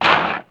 SPADE_Dig_07_mono.wav